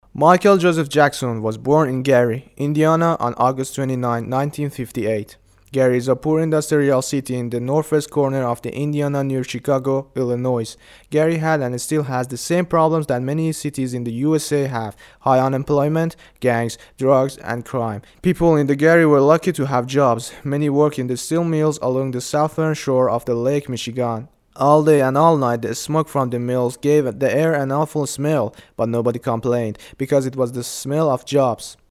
Narration
Male
Young
English (Local accent)